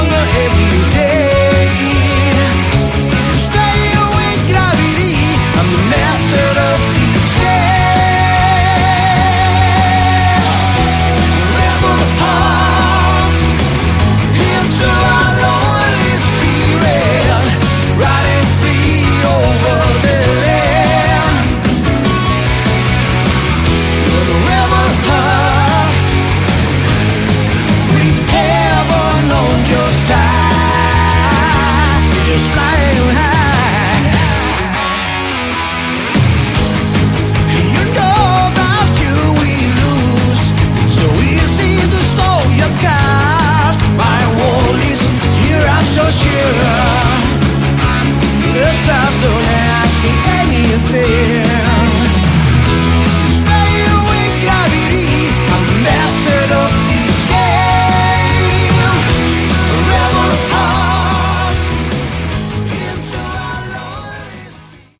Category: Melodic Rock
Vocals
Guitar
Keyboards
Drums
Bass